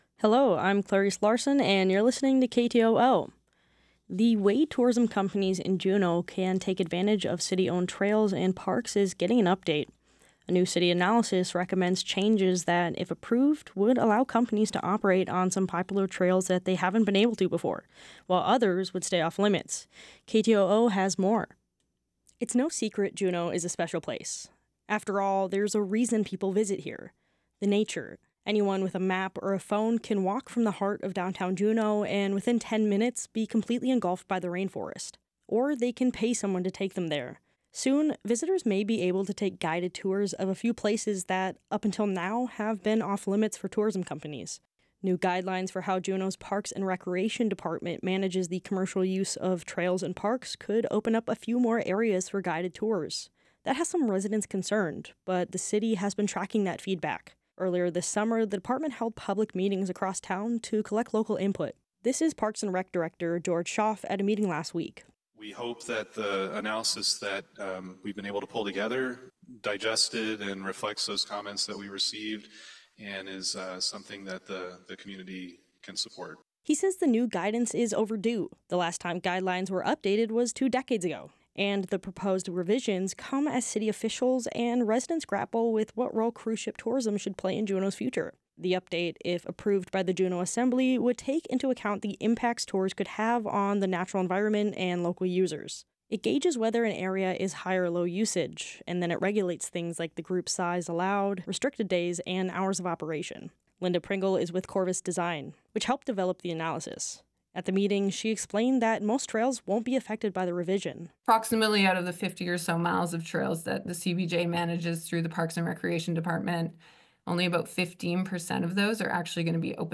Newscast - Monday, Dec. 09, 2024